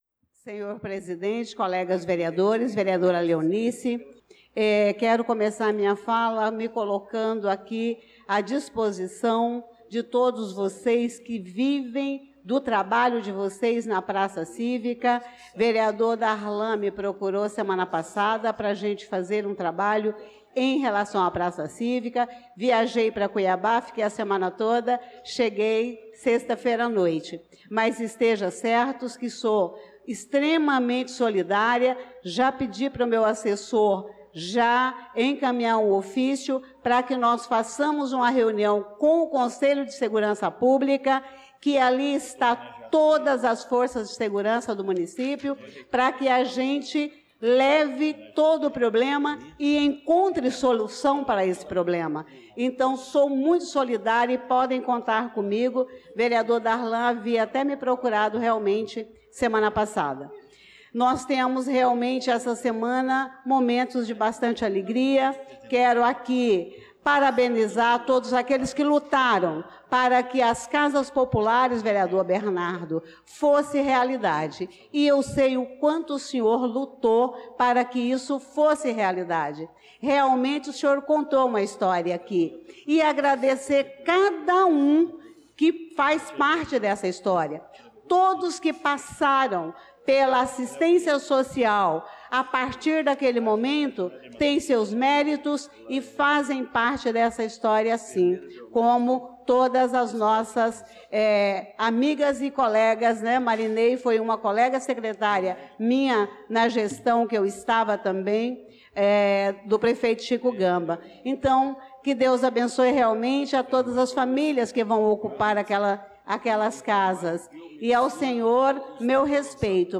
Pronunciamento da vereadora Elisa Gomes na Sessão Ordinária do dia 02/06/2025